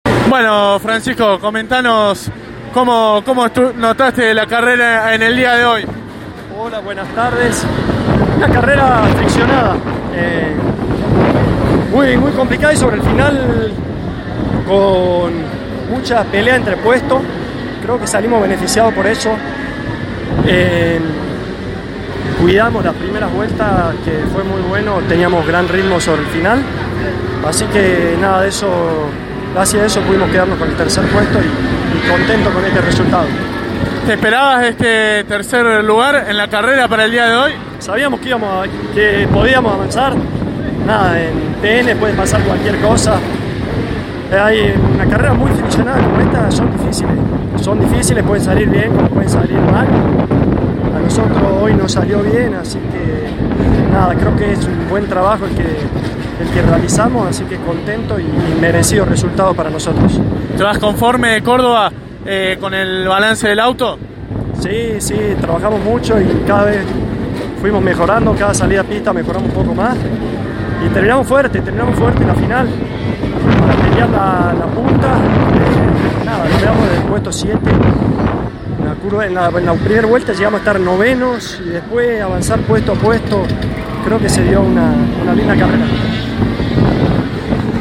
Por ello, tras concluida la final de Clase 2, dialogó con los protagonistas que aquí podrás escuchar.